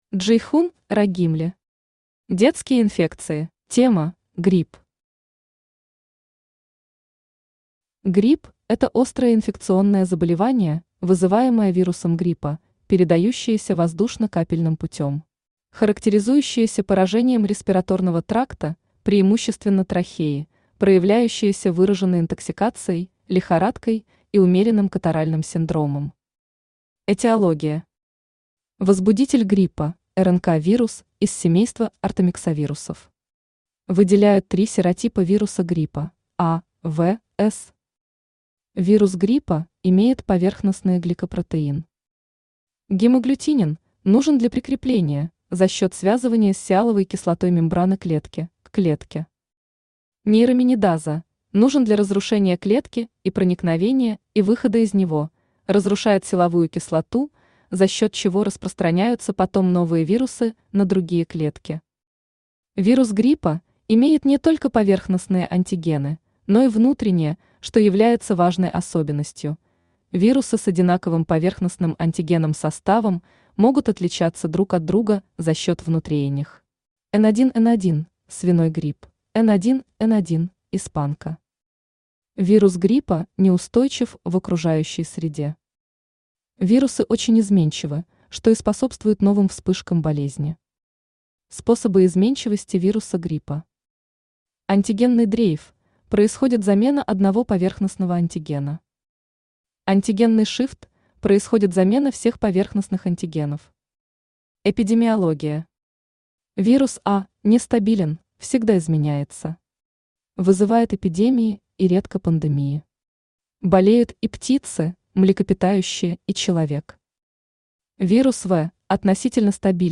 Аудиокнига Детские инфекции | Библиотека аудиокниг
Aудиокнига Детские инфекции Автор Джейхун Рагимли Читает аудиокнигу Авточтец ЛитРес.